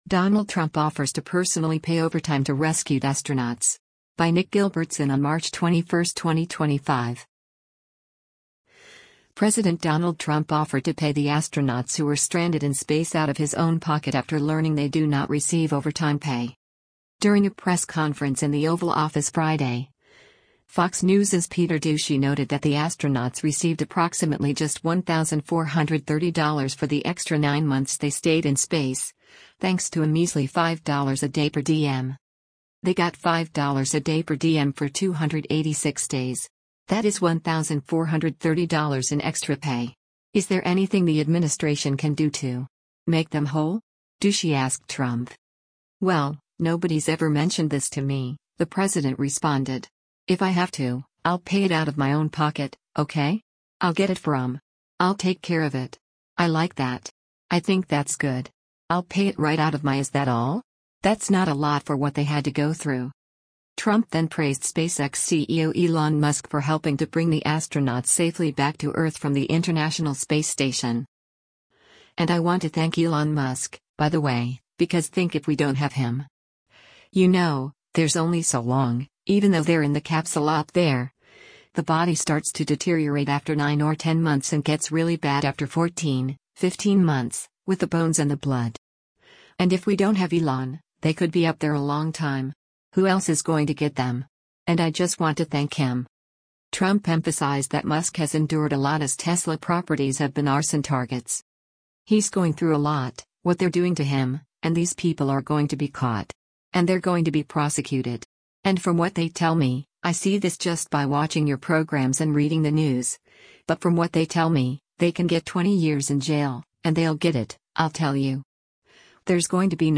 During a press conference in the Oval Office Friday, Fox News’s Peter Doocy noted that the astronauts received approximately just $1,430 for the extra nine months they stayed in space, thanks to a measly $5 a day per diem.